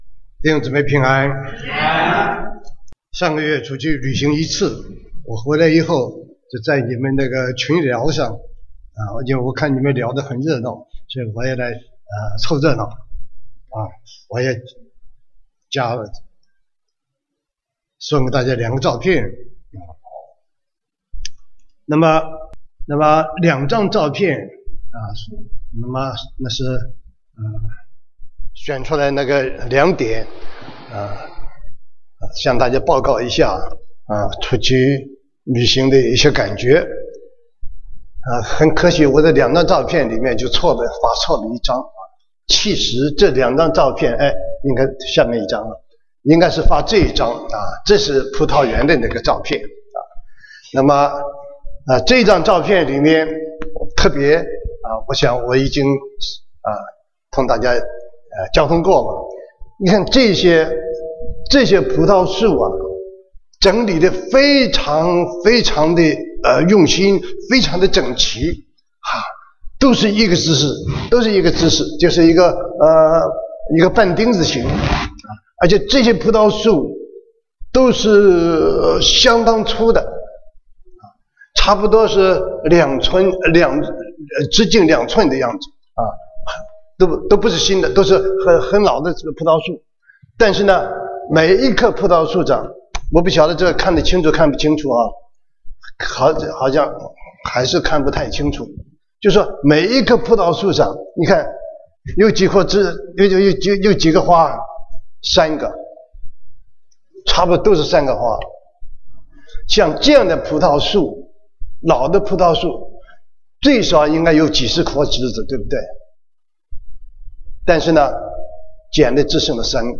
2016 Sermons